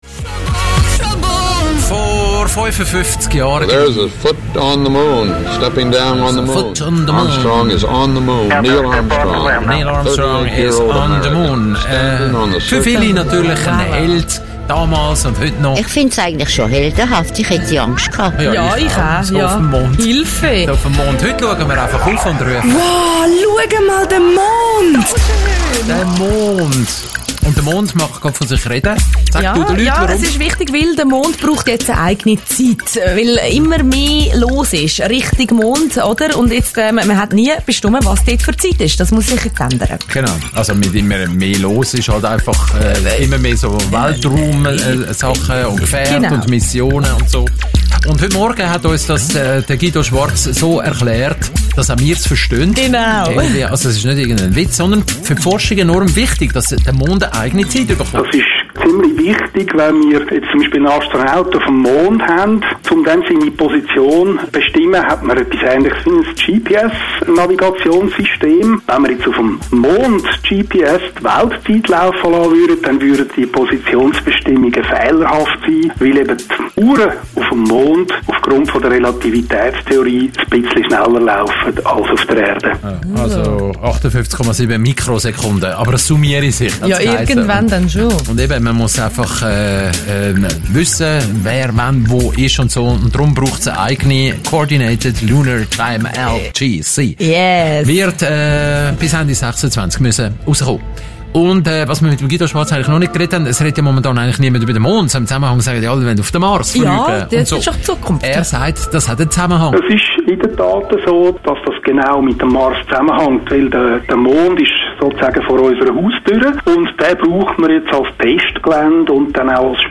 Radiobeitrag Teil 3